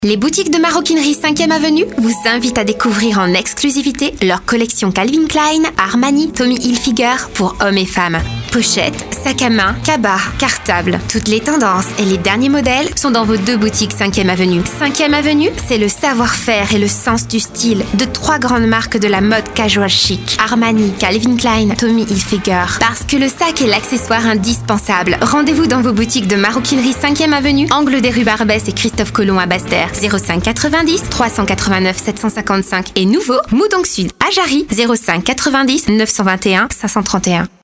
Publicité magasin de vêtements